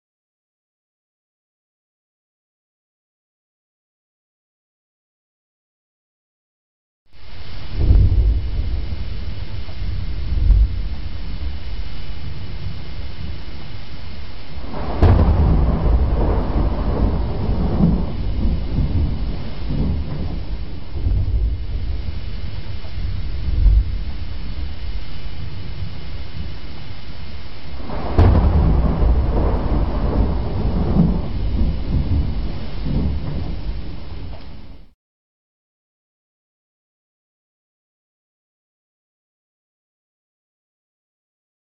LLUVIA Y TRUENOS THE THUNDER LIGHT SOUND
Ambient sound effects
lluvia_y_truenos__THE_THUNDER__LIGHT_SOUND.mp3